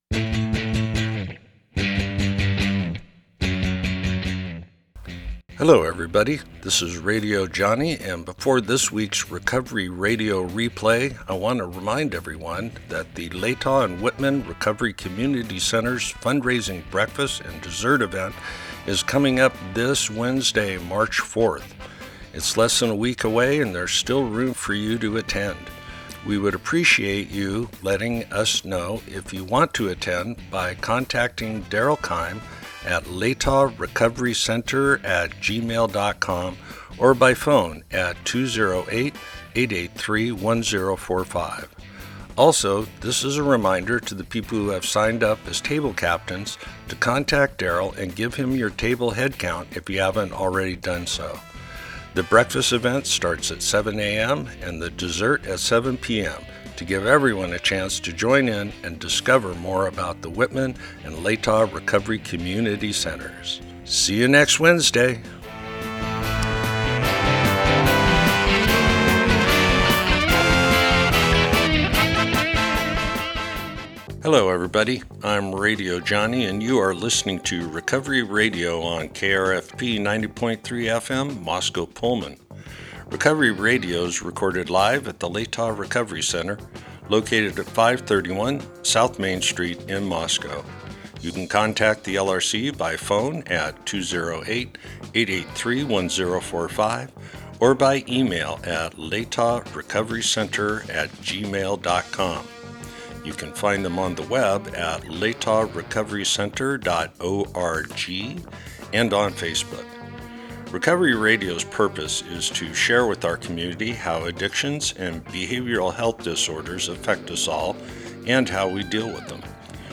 Recovery Radio is originally broadcast on KRFP FM in Moscow, ID.